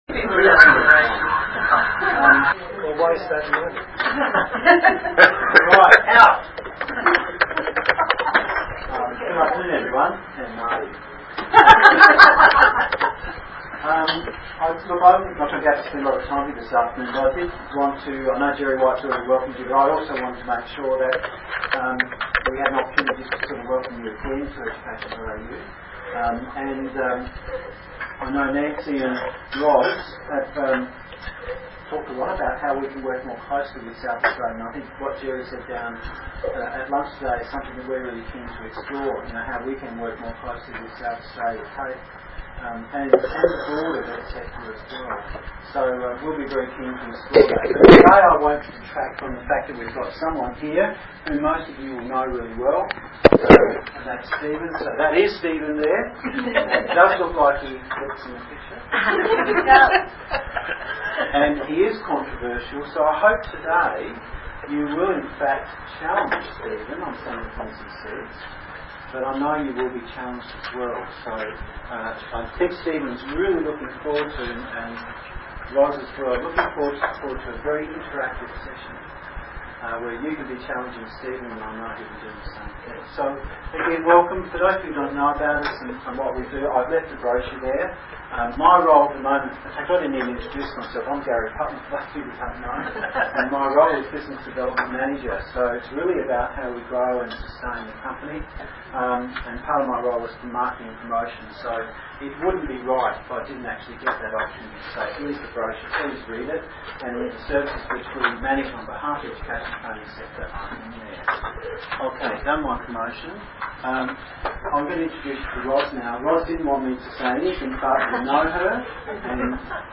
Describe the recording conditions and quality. This workshop explored the need for and methods of collaboration available to TAFE SA staff. It was very unstructured (mostly because I didn't know what the topic was until we started). Special Session, Flexible Learning Leaders, Adelaide, SA, Australia, Seminar, Sept 29, 2004.